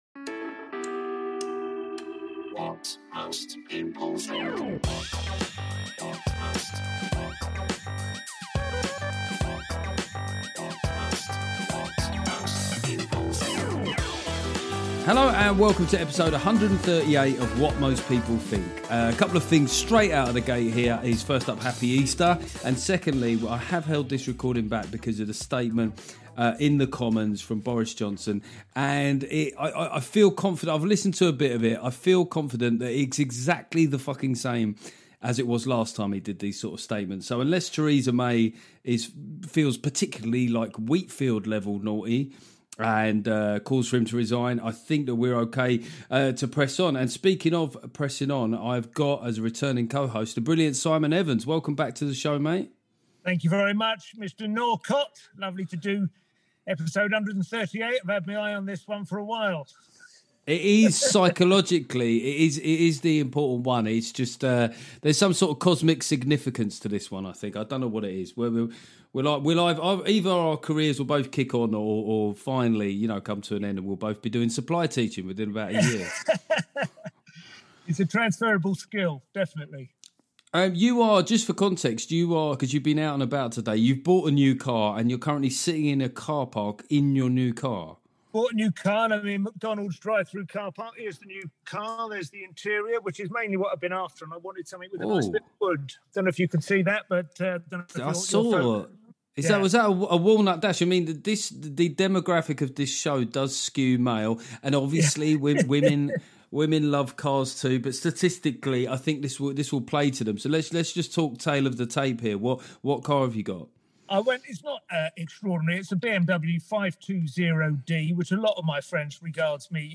Simon Evans co-hosts this week and it's a brilliant rattle through so many subjects.